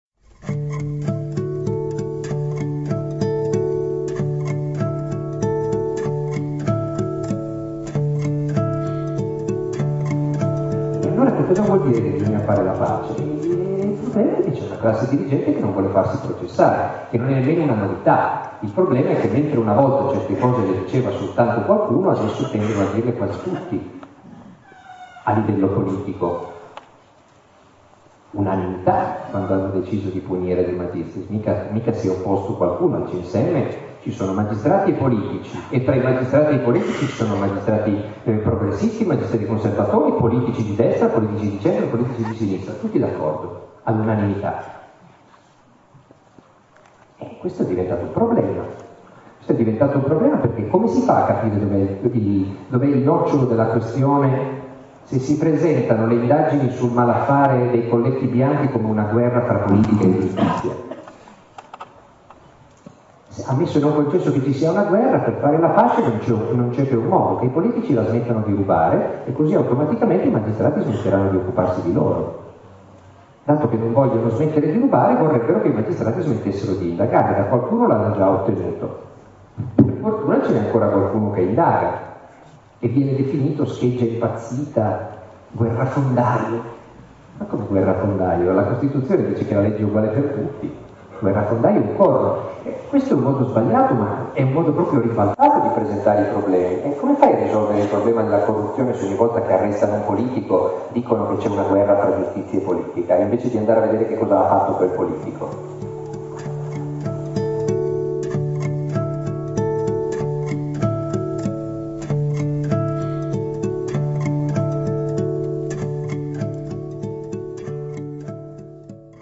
Marco Travaglio, 21 febbraio 2008, teatro Ariston, Giulianova (Te)